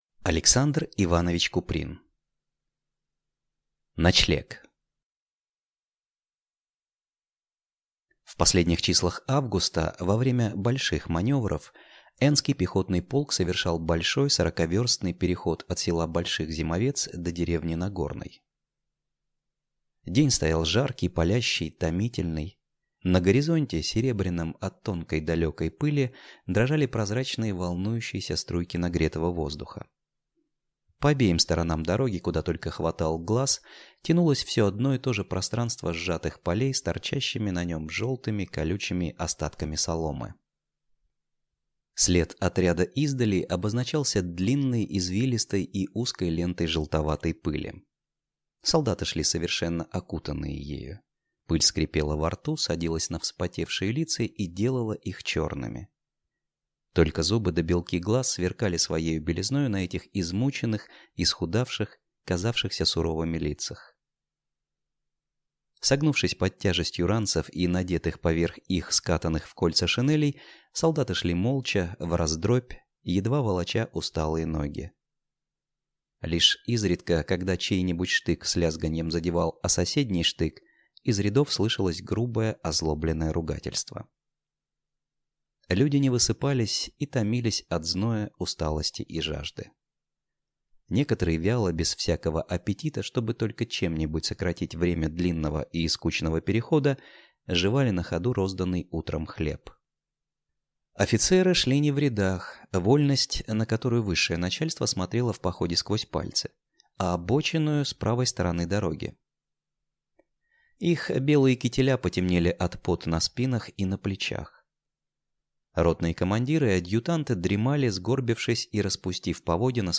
Аудиокнига Ночлег | Библиотека аудиокниг
Aудиокнига Ночлег Автор Александр Куприн